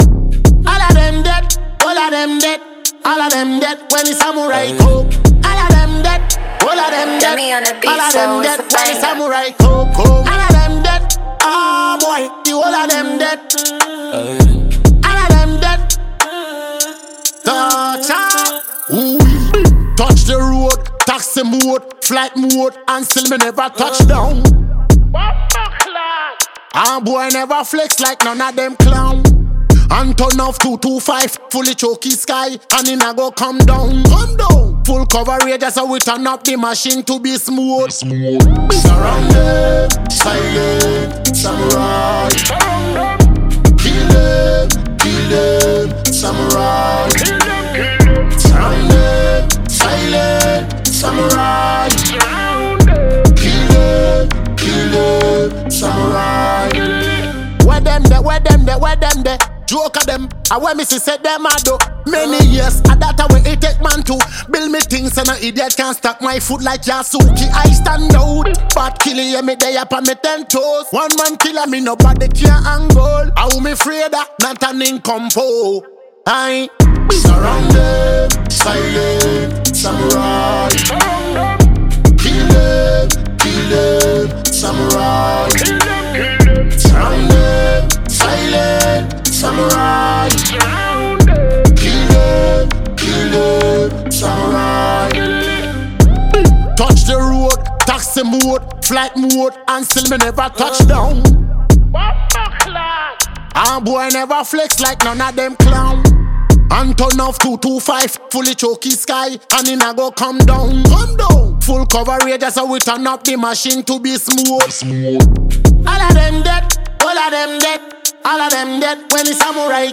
afro-dancehall